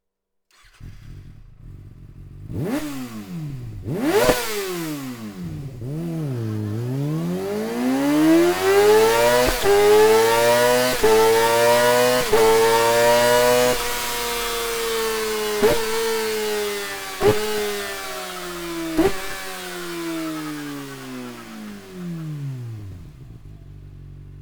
Akrapovic Slip-On Line (Carbon) Endschalldämpfer mit Carbon-Hülle und Carbon-Endkappe, ohne Straßenzulassung; für
Sound Akrapovic Slip-On